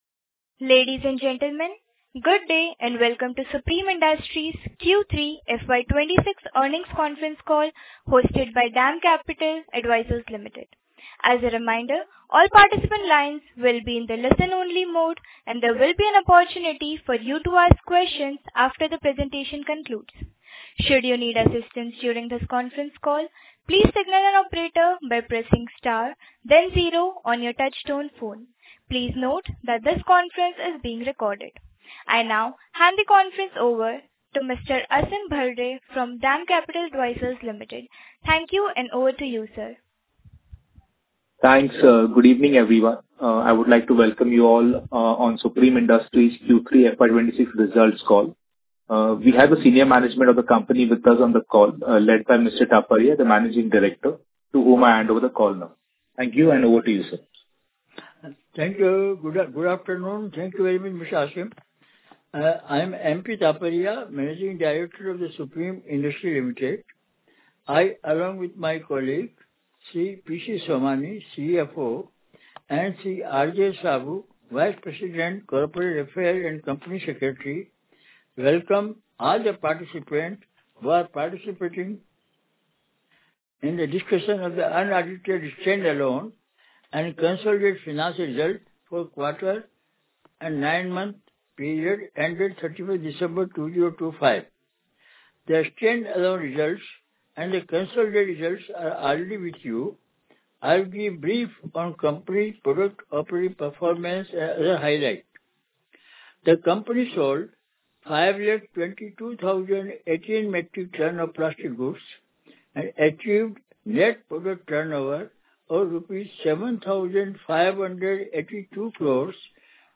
Analyst Call Recording
Supreme Industries has provided access to the audio recording of the conference call held for analysts and investors on January 21, 2026.